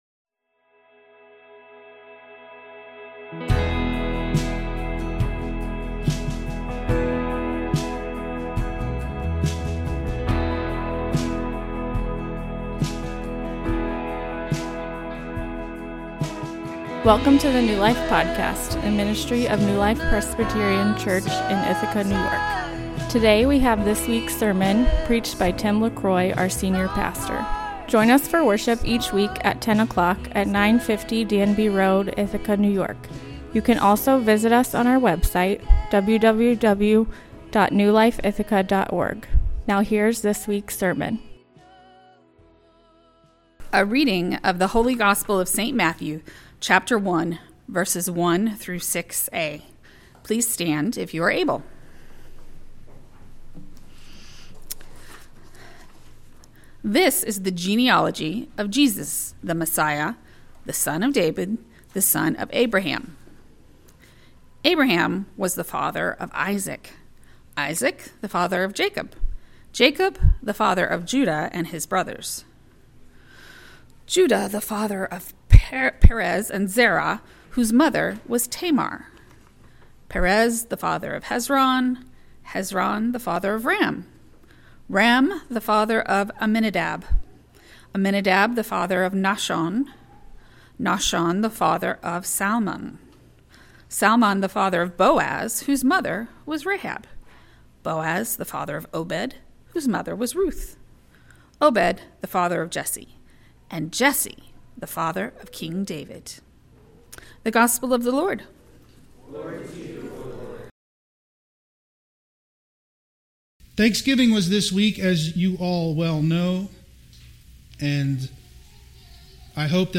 A sermon on Joshua 2:1-24 and Matthew 1:1-5 Sermon Outline: 1.